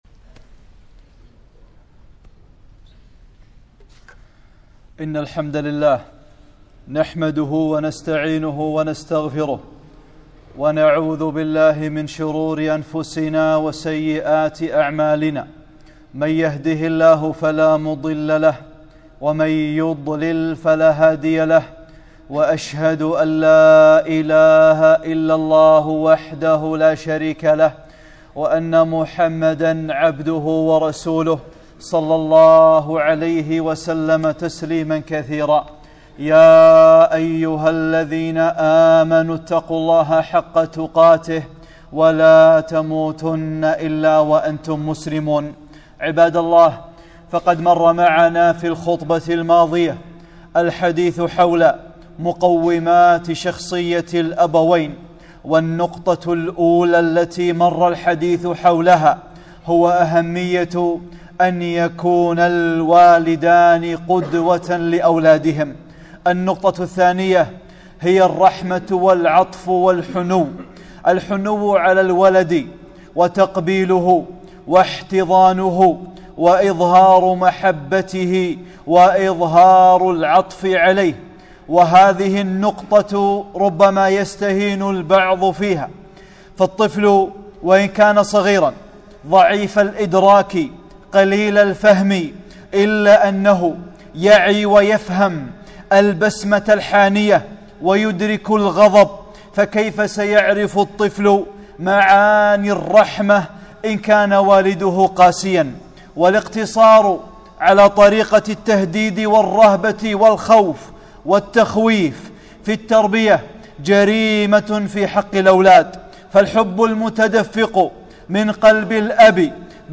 (22) خطبة - رحمة الصغير ومخالطته